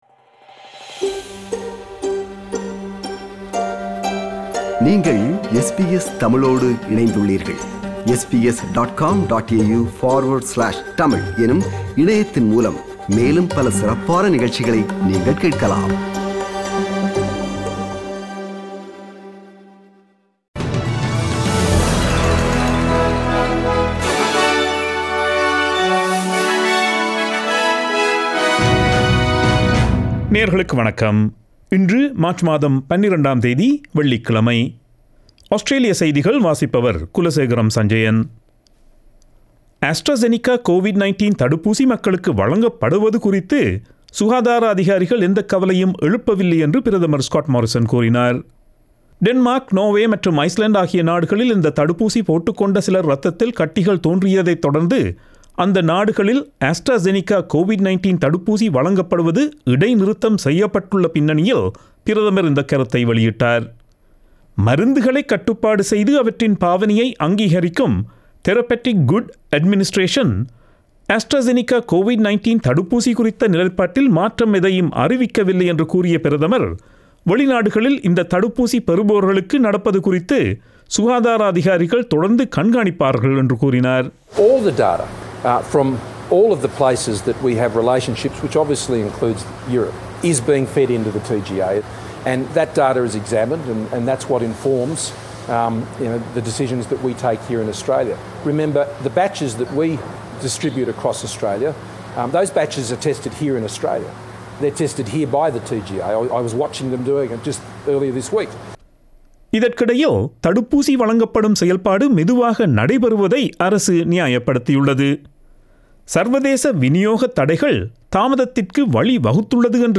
Australian news bulletin for Friday 12 March 2021.